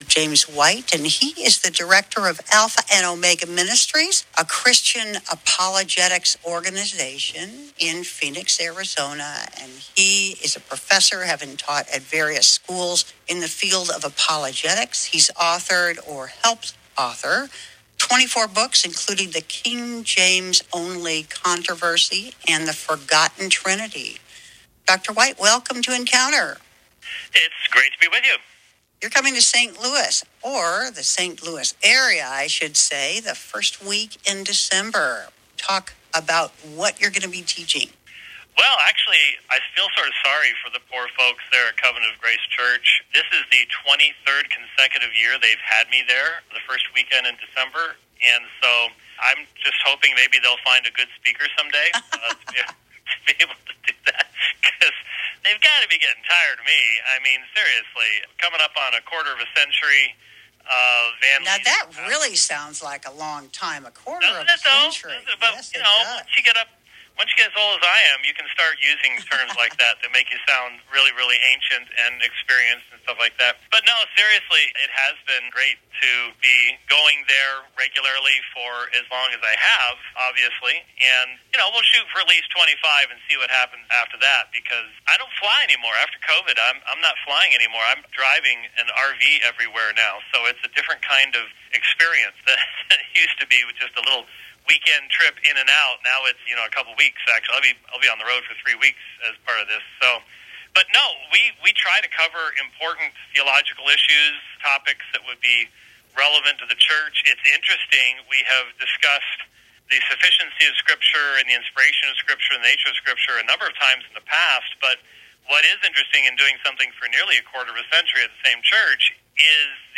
KSIV Radio Interview - Sola Scriptura